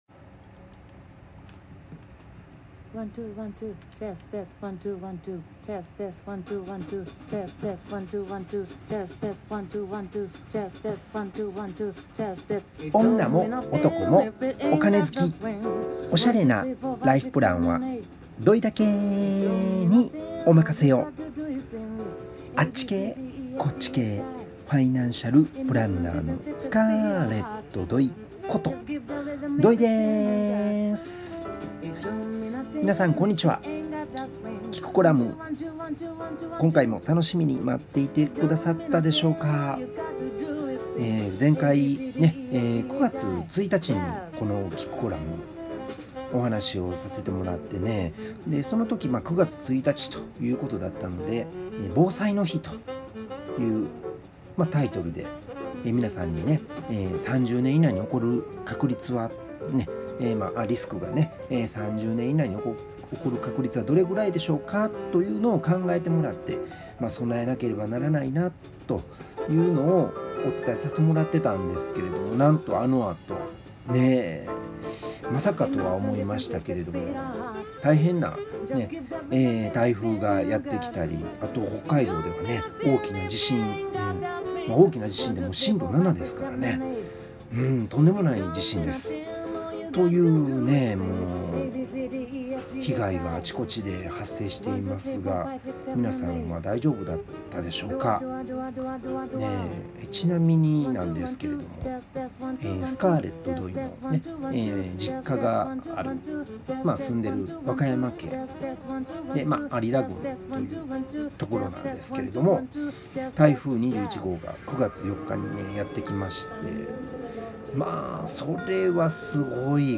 ＦＭちゃおラジオ番組 毎週木曜日の１５時～１５時２５分「すまいるらいふ」